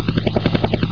rotors.wav